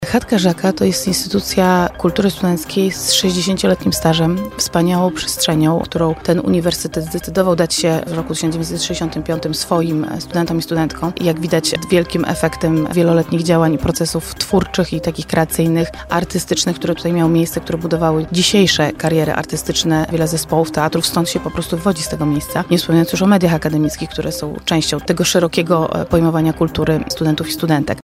Opublikowano w Aktualności, Kultura, Poranna Rozmowa Radia Centrum, Wydarzenia